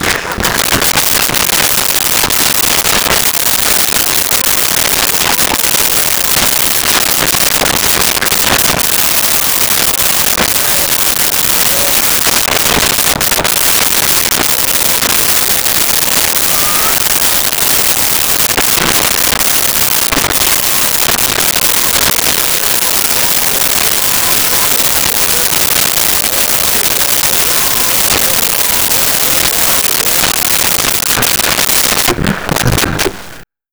Laughing Small Male Crowd
Laughing Small Male Crowd.wav